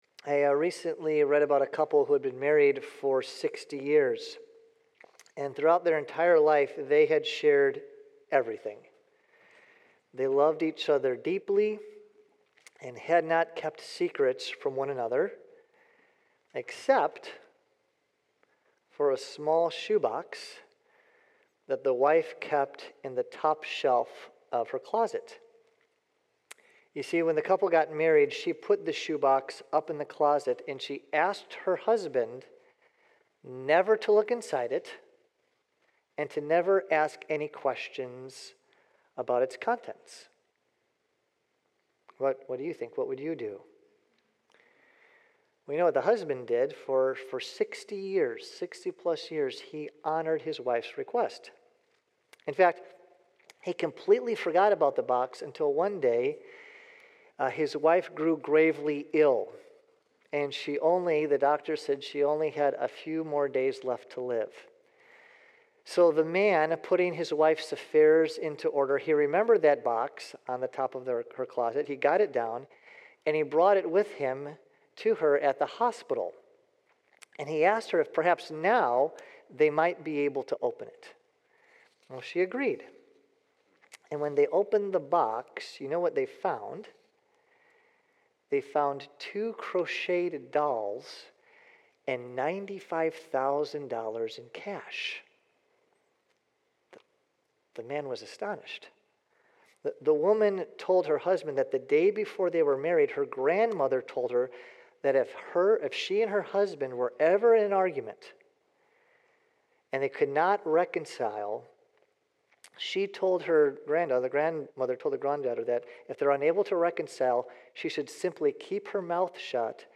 1 Sermon: The Death of Divine Absence (Good Friday 2025) 33:33